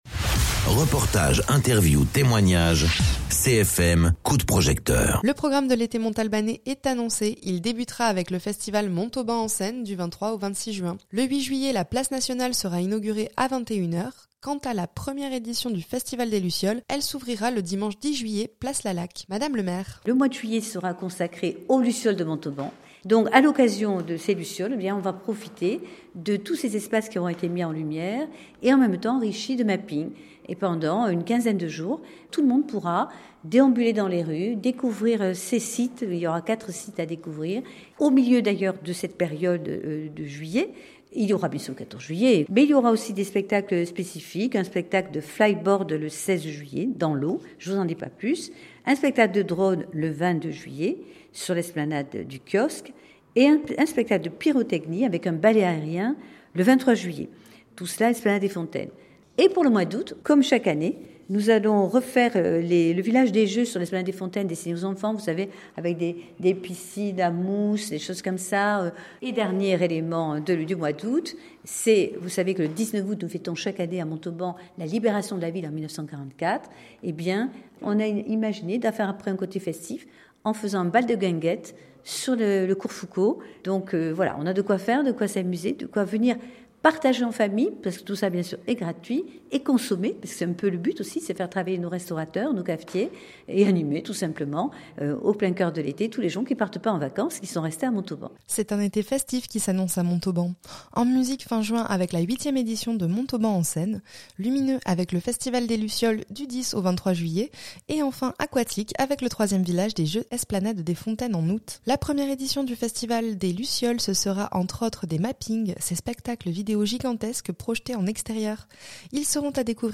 Interviews
Invité(s) : Brigitte Barèges